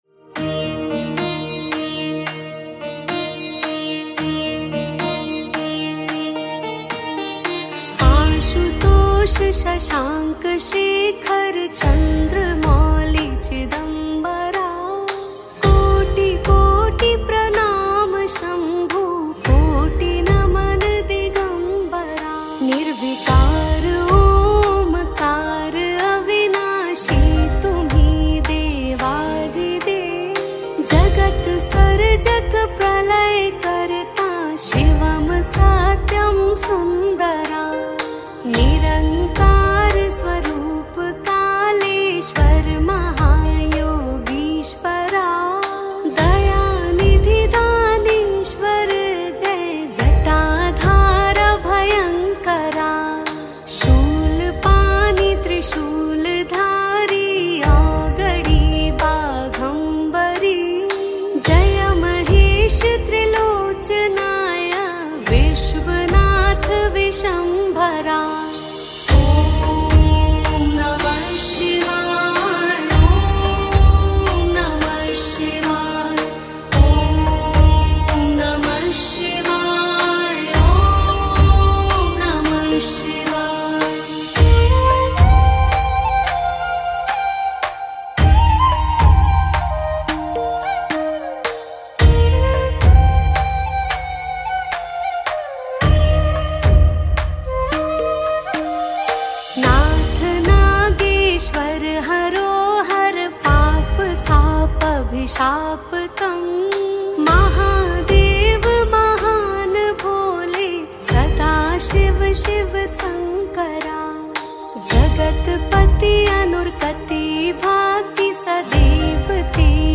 Releted Files Of Bhakti Gana